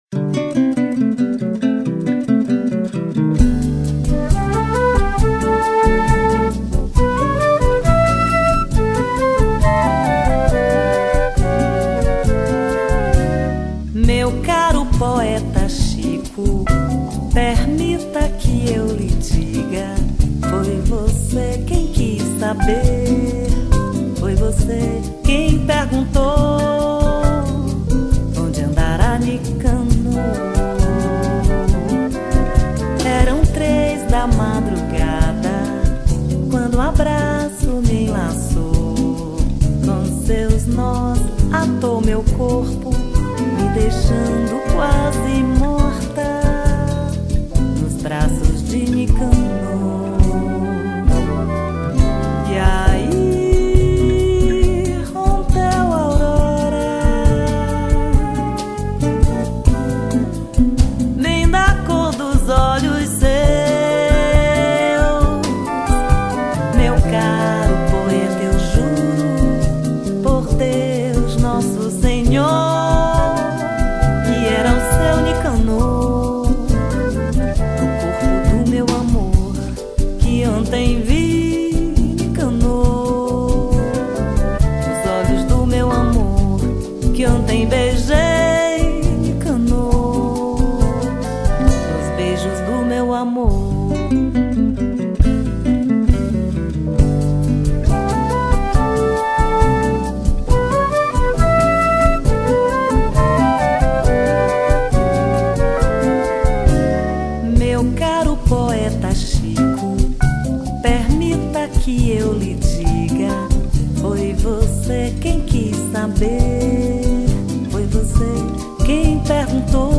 1833   03:24:00   Faixa:     Bossa nova